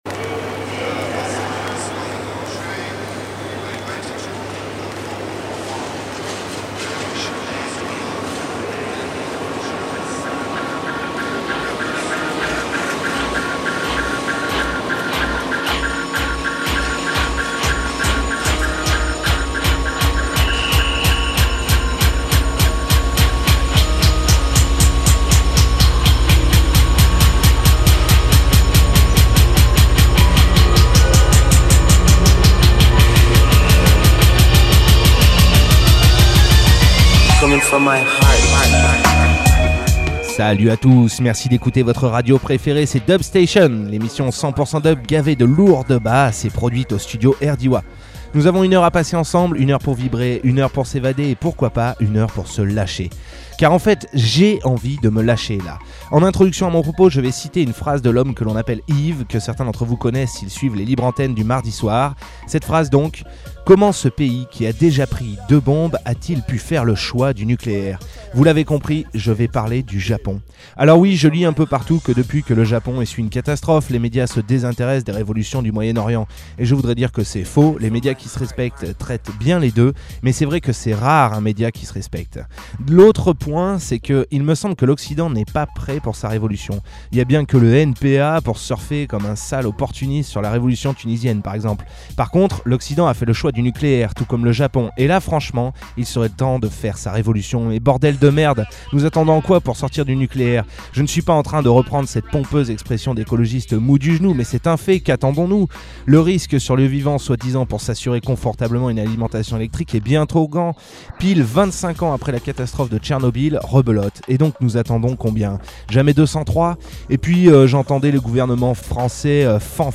bass music , dub , musique , musique electronique , reggae